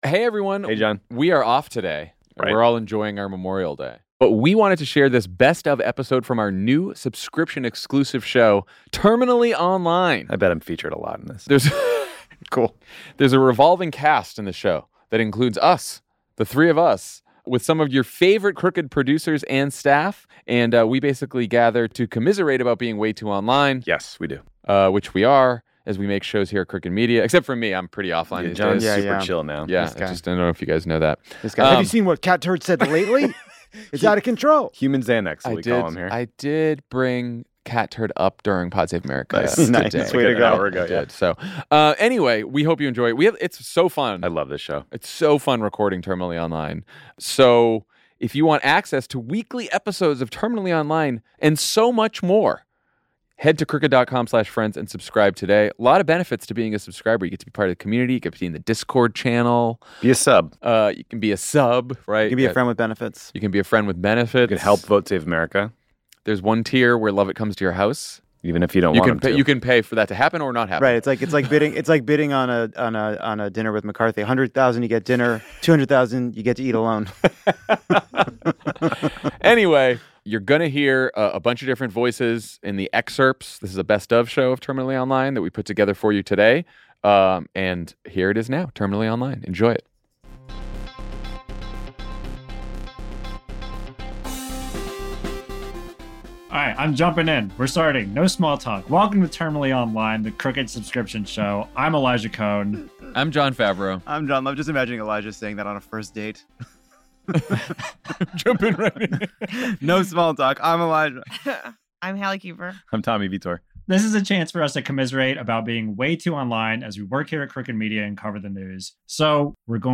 In this behind-the-scenes podcast, Pod Save America hosts and Crooked Media producers commiserate about being way too online as they make their shows.